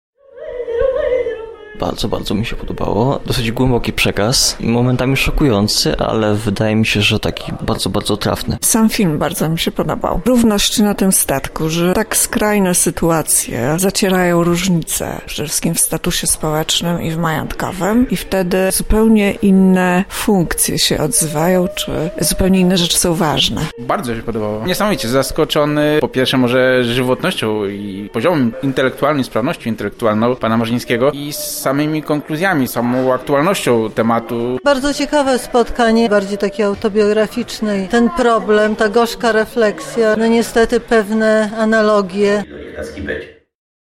zapytał uczestników o wrażenia po spotkaniu.